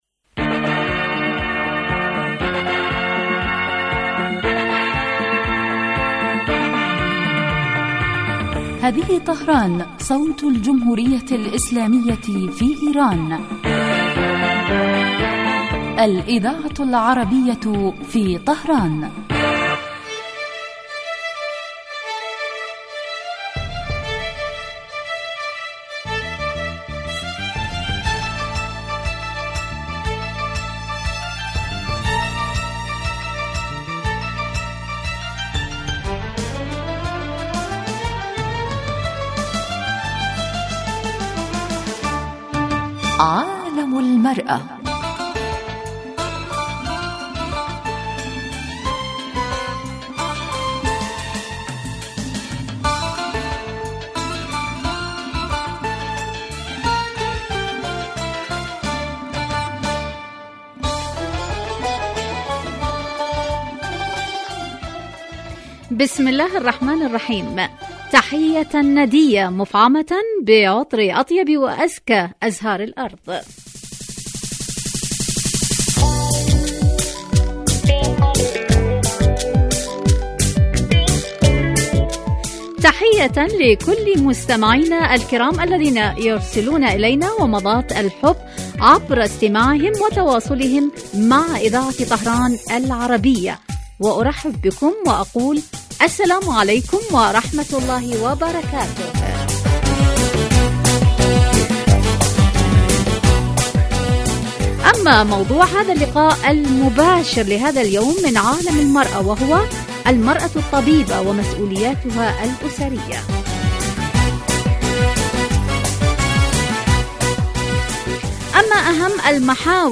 من البرامج الناجحة في دراسة قضایا المرأة فی العالمین الإسلامي و العربي ومعالجة ما لها من مشکلات و توکید دورها الفاعل في تطویر المجتمع في کل الصعد عبر وجهات نظر المتخصصین من الخبراء و أصحاب الرأي مباشرة علی الهواء